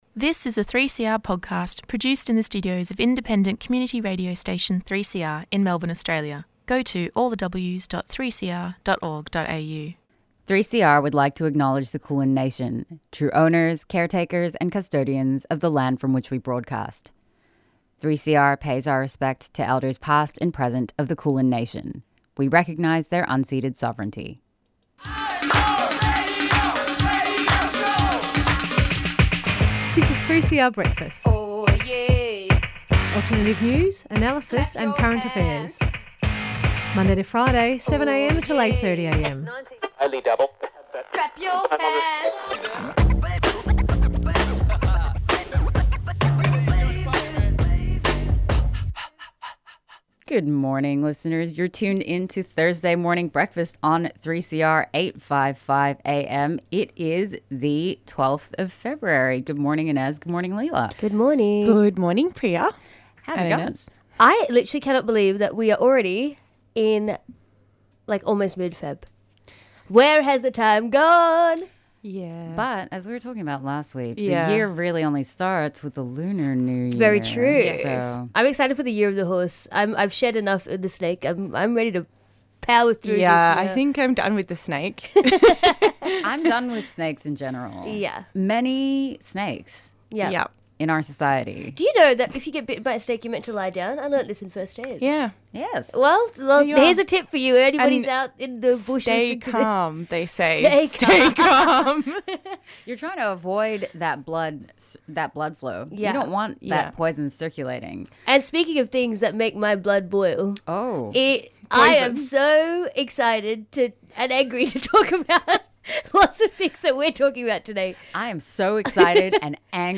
Acknowledgement of Country//Headlines// Masafer Yatta// We heared updates from an 'australian' activist currently in Palestine on recent developments in the area of Masafer Yatta, a collection of herding communties in the West Bank.